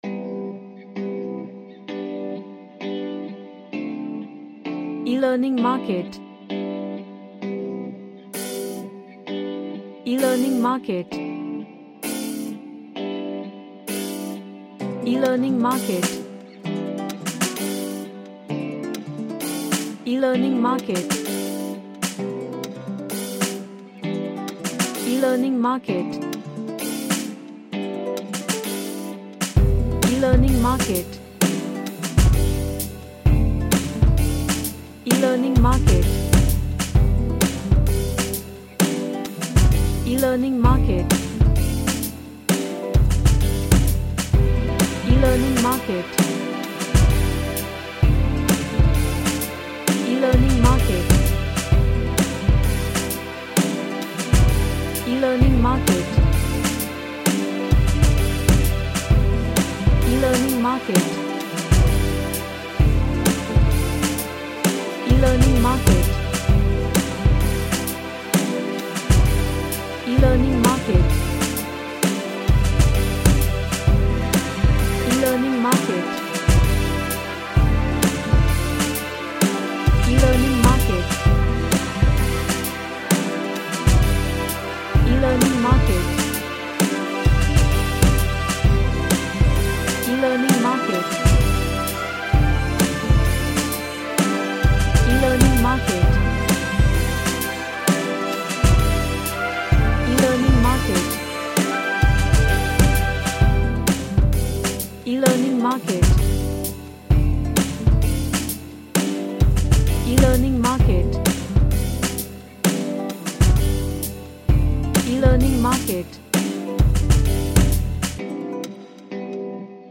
An Orchestral ambient track with orchestral elements
Epic / Orchestral